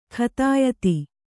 ♪ khatāyati